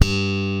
ALEM SLAP G2.wav